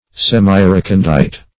Meaning of semirecondite. semirecondite synonyms, pronunciation, spelling and more from Free Dictionary.